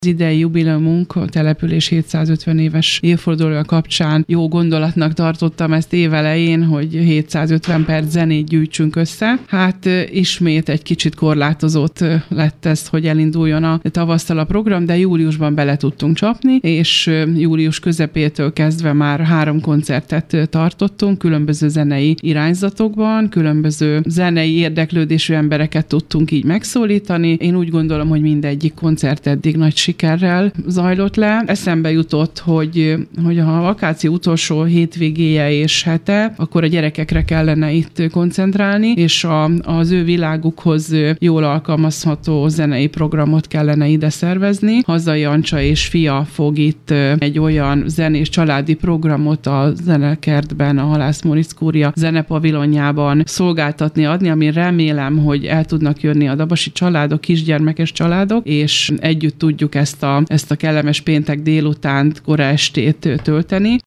Pálinkásné Balázs Tünde alpolgármestert hallják: